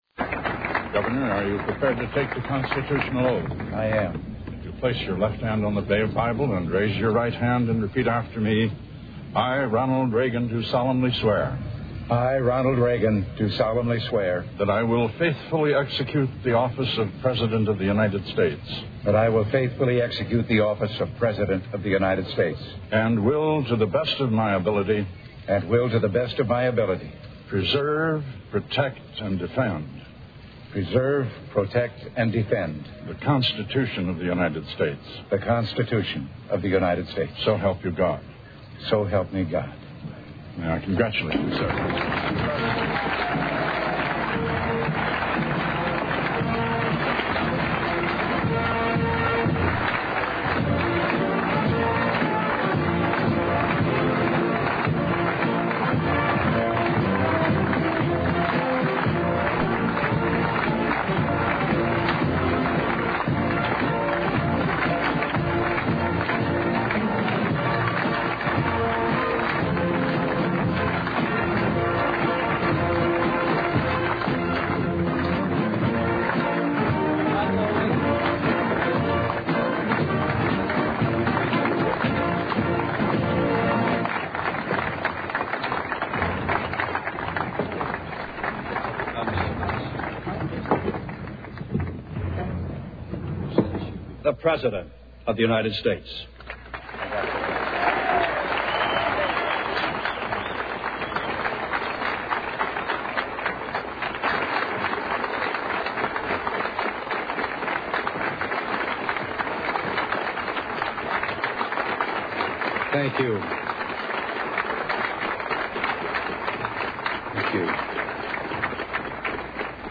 [Swearing-in on Ronald Reagan by USSC Chief Justice Warren Burger]